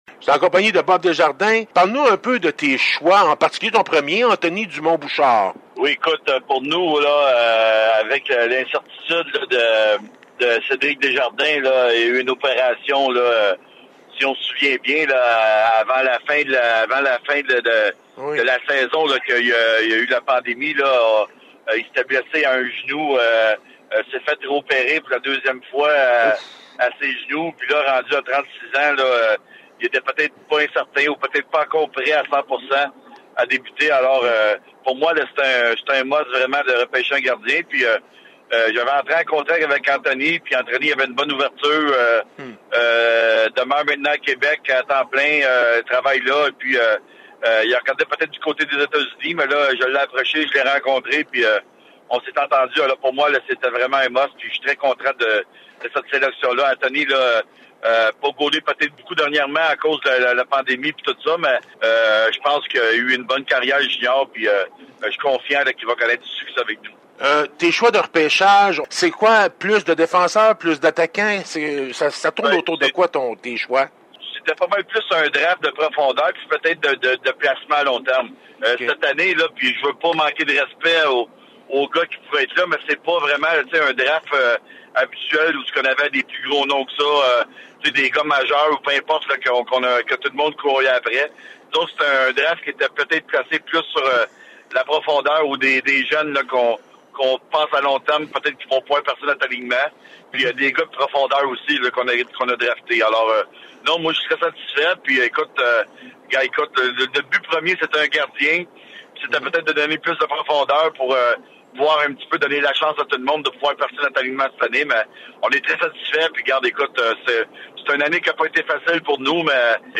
J'ai donc fait ces entrevues par téléphone.
Autant vous le dire tout de suite, la qualité sonore est loin d'être parfaite.